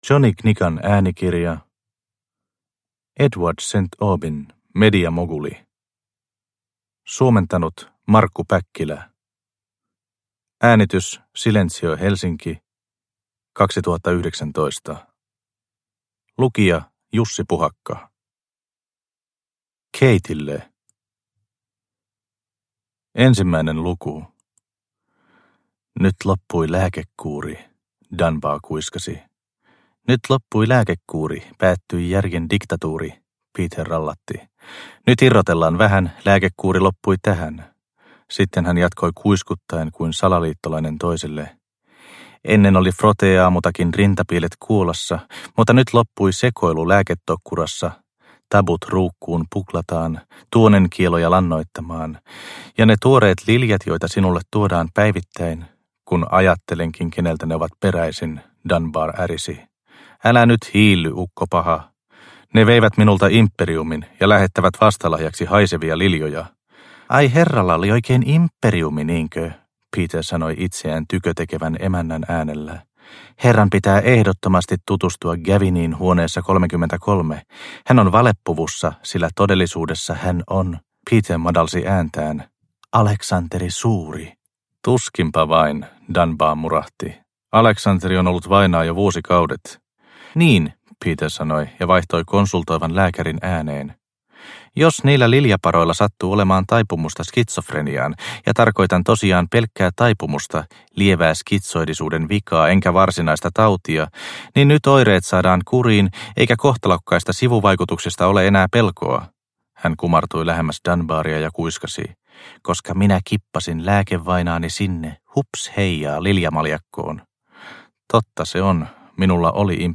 Mediamoguli – Ljudbok – Laddas ner